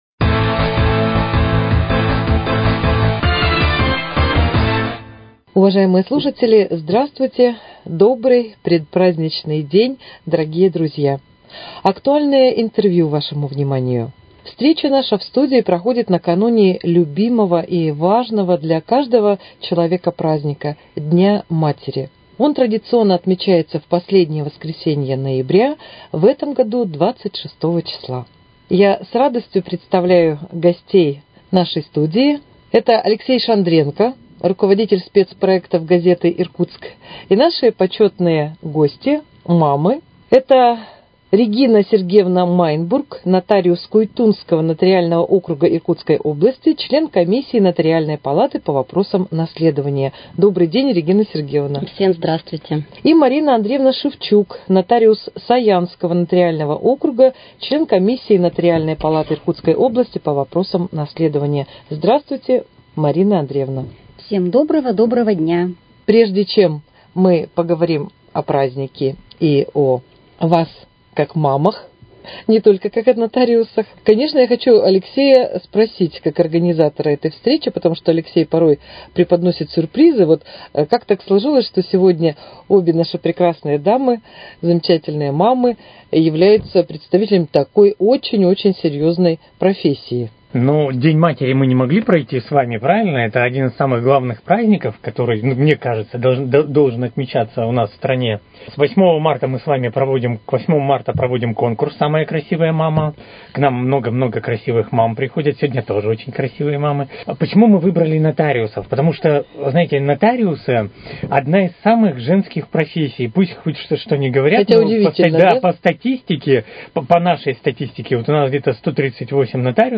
Актуальное интервью: Передача, посвященная Дню матери